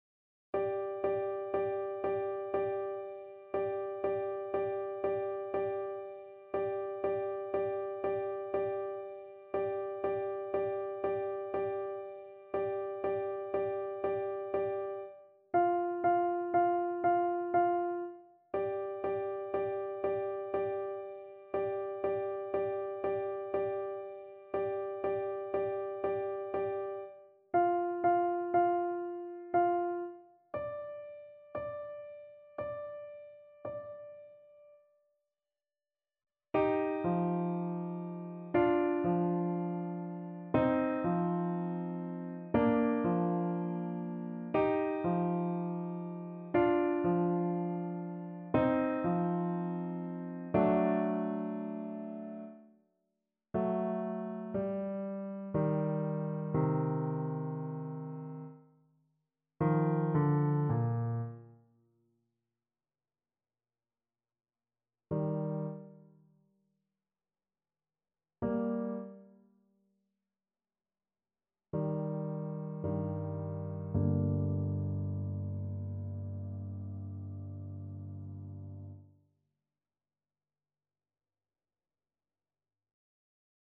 Play (or use space bar on your keyboard) Pause Music Playalong - Piano Accompaniment Playalong Band Accompaniment not yet available transpose reset tempo print settings full screen
G minor (Sounding Pitch) (View more G minor Music for Trombone )
3/4 (View more 3/4 Music)
Andante sostenuto =60
Classical (View more Classical Trombone Music)